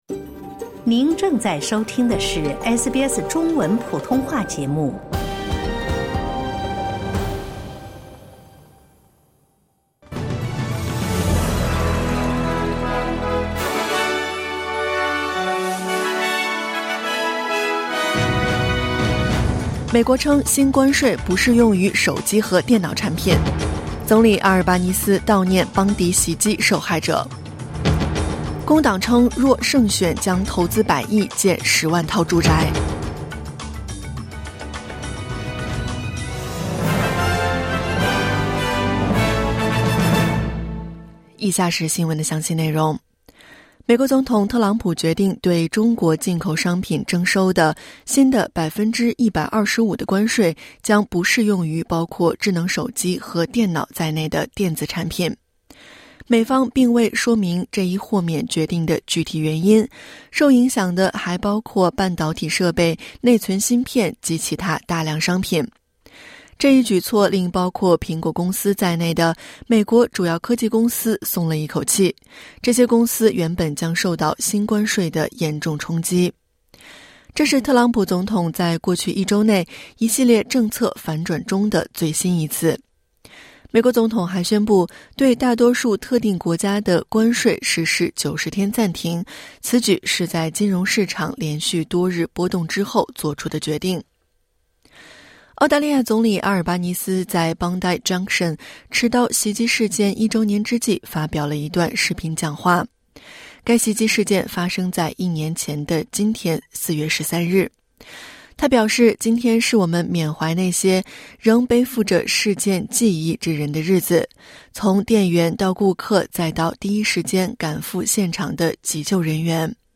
SBS早新闻（2025年4月13日）